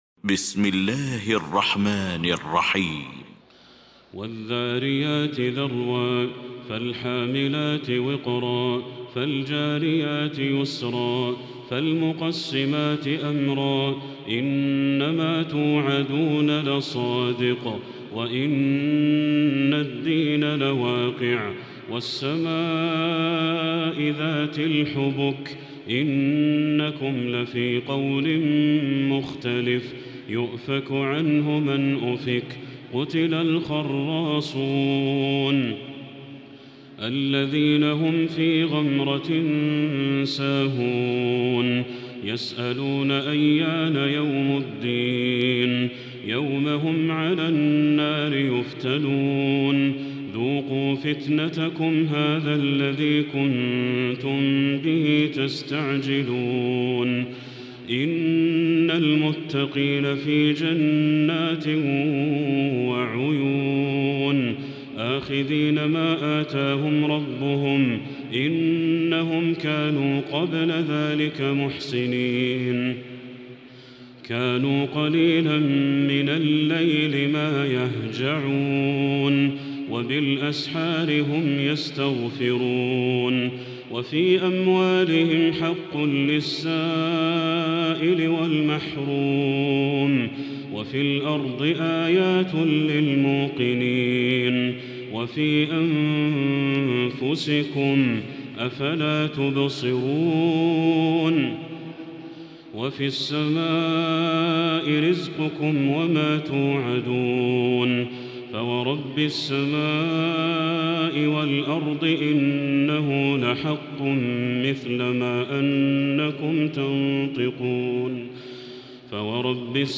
المكان: المسجد الحرام الشيخ: بدر التركي بدر التركي الذاريات